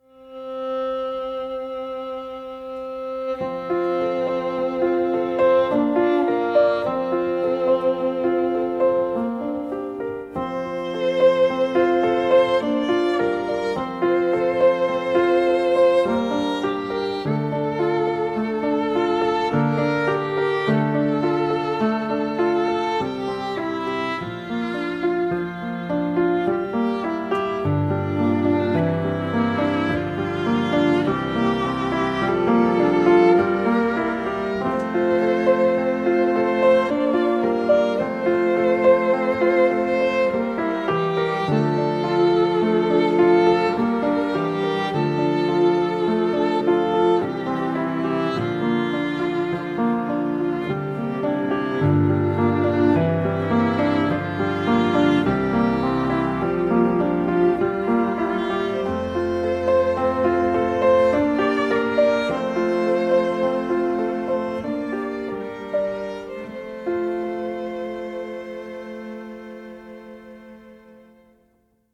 Musique originale